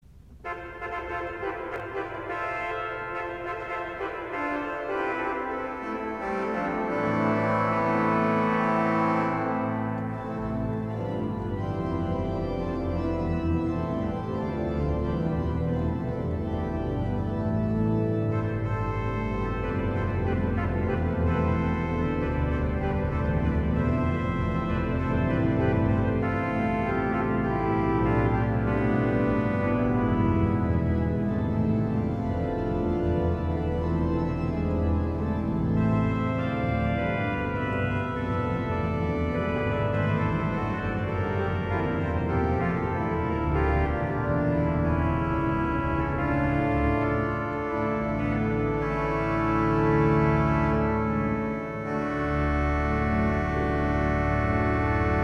R8_Tuba Mirabilis.mp3